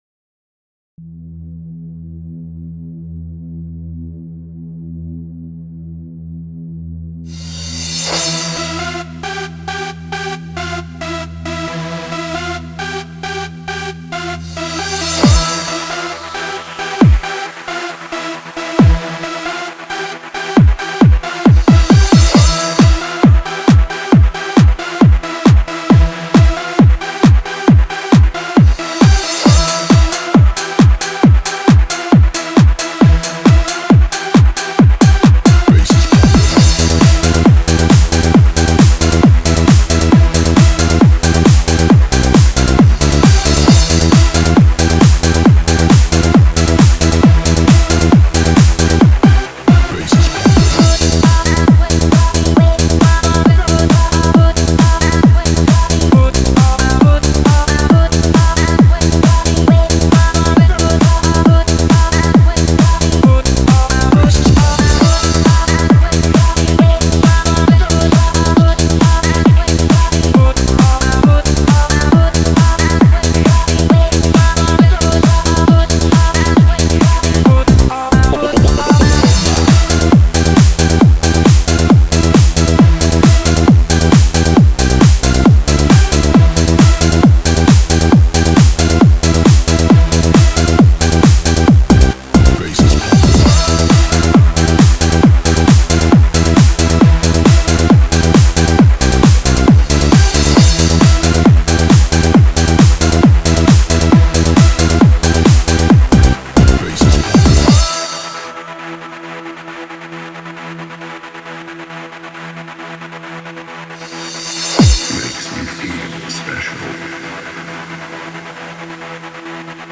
Eurodance__style